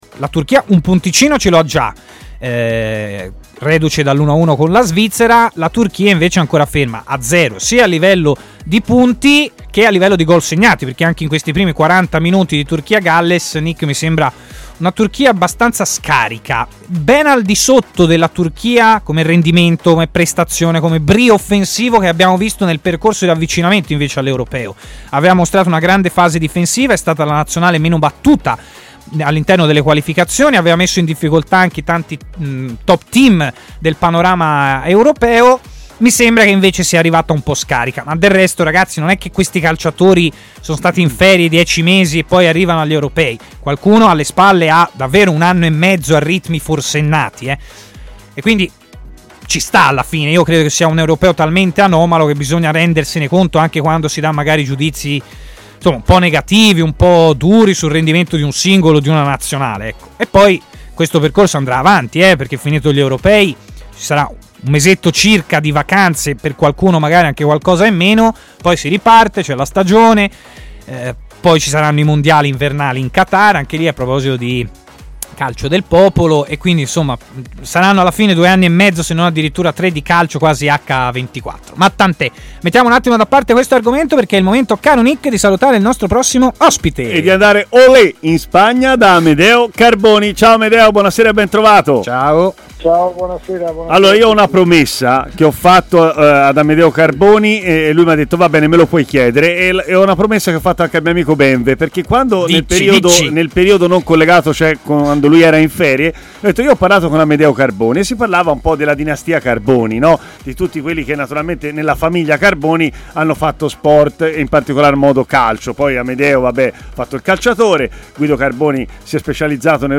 L'ex difensore Amedeo Carboni ha parlato in diretta a Stadio Aperto, trasmissione di TMW Radio